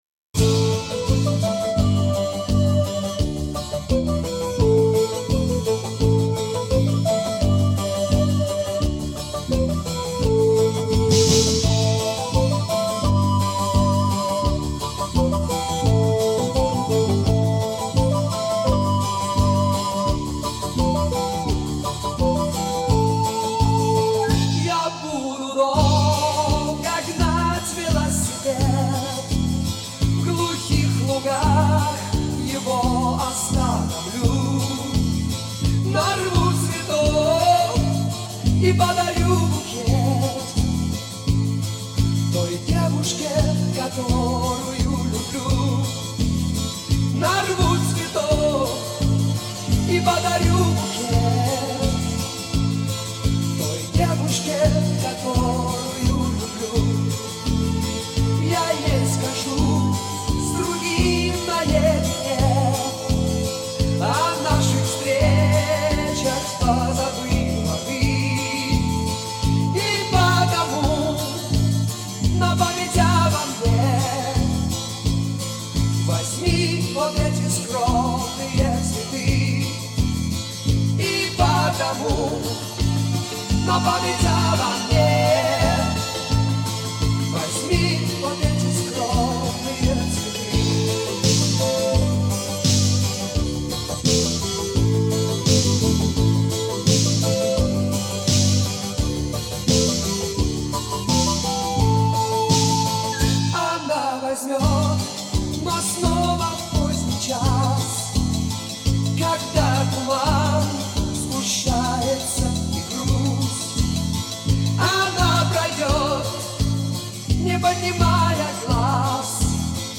Прекрасная работа, и музыка душевная!!!